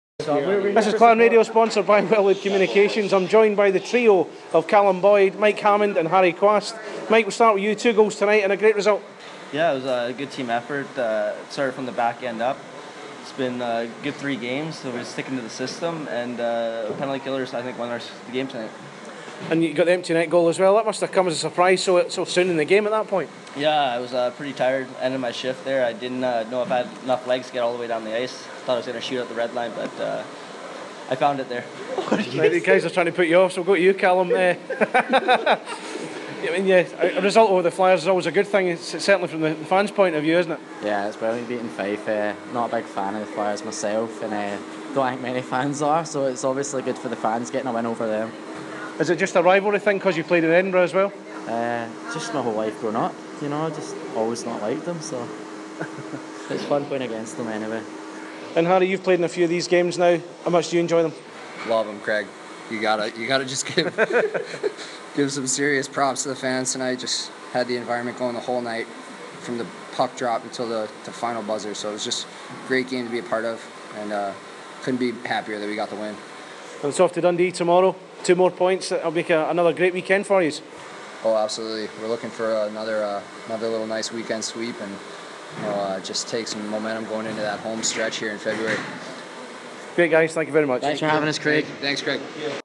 spoke to Clan Radio after the team's 5-2 win over Fife Flyers